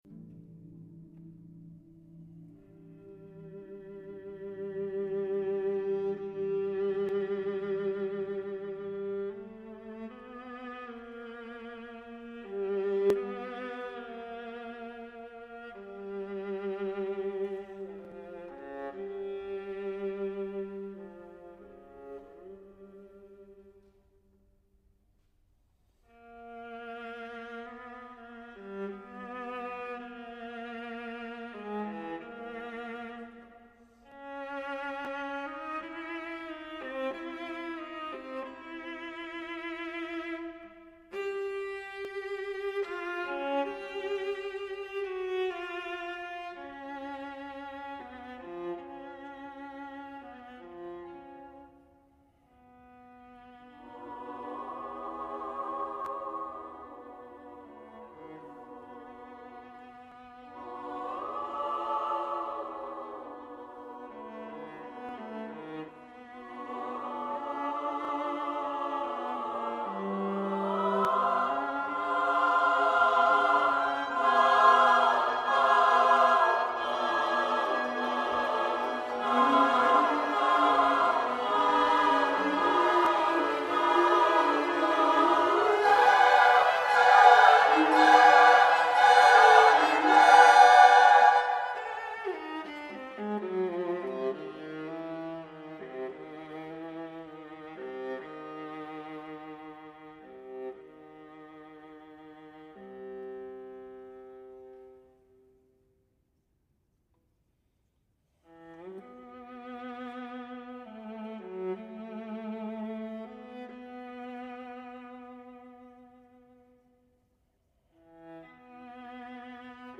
a suite for viola, orchestra, and wordless chorus.